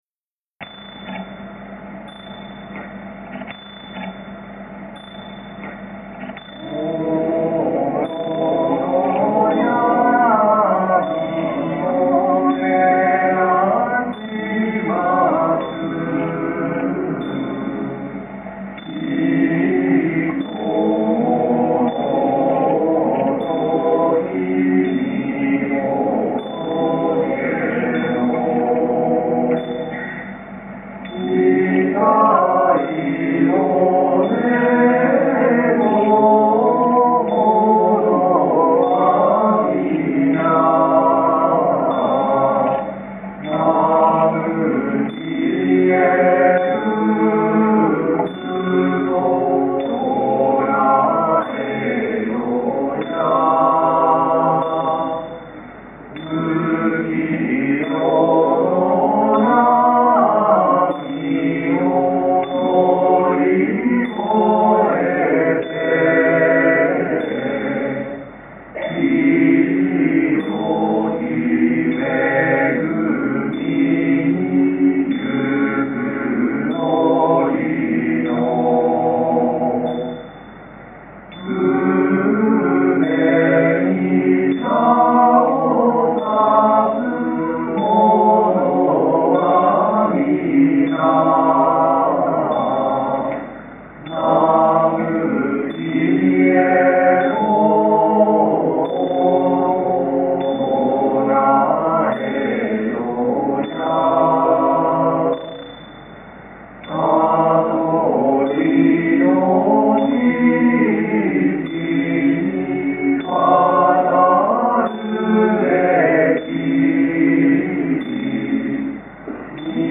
第１６回梅花流千葉県奉詠大会
１７名の会員が登壇し、歌い出しは多少ずれたところはありましたが、たくさんの拍手を頂くことができました。
♪本番でお唱えいたしました三宝讃歌の音源です♪